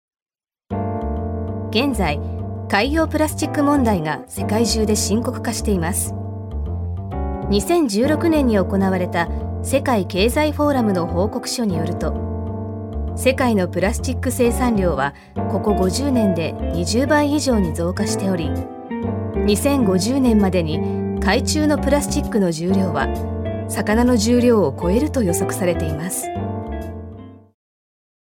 女性タレント
ナレーション４